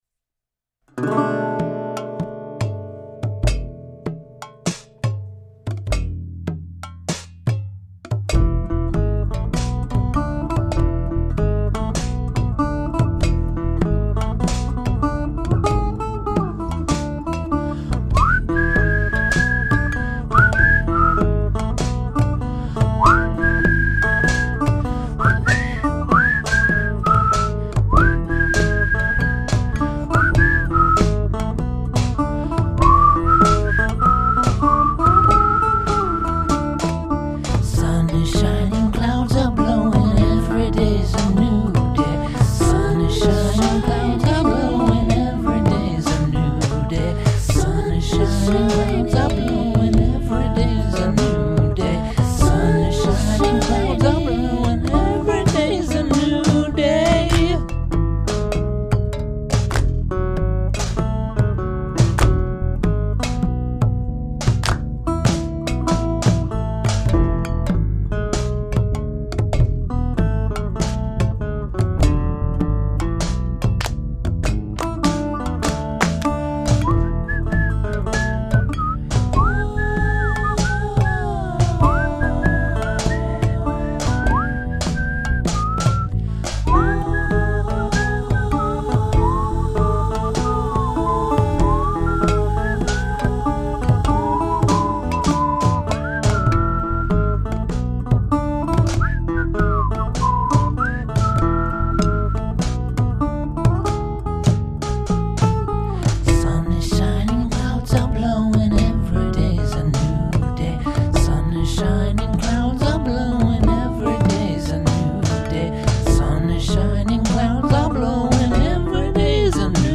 odd songs and instrumentals
acoustic instruments, lap steel and electric bass